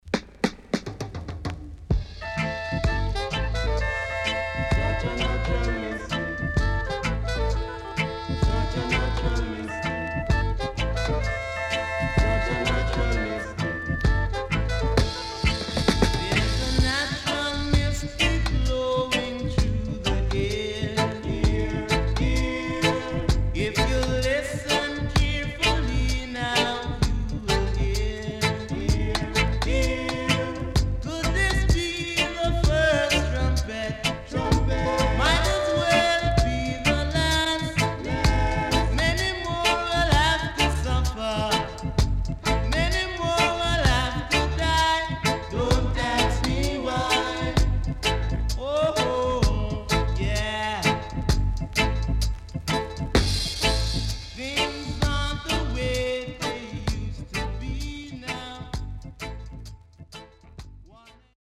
SIDE A:少しノイズ入りますが良好です。
SIDE B:少しノイズ入りますが良好です。